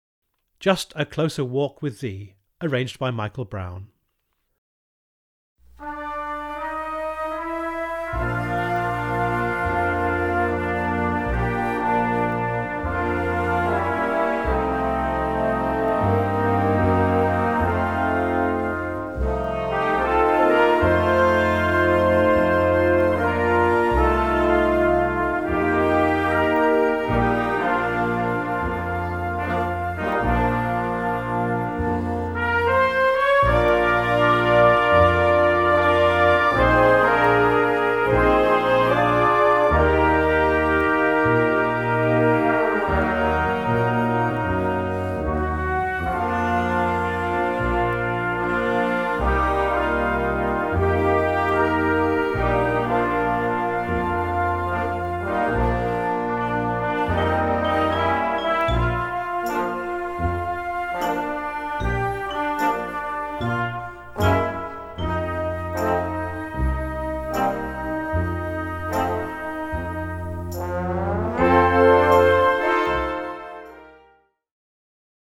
Gattung: Flex Band (5-stimmig)
Besetzung: Blasorchester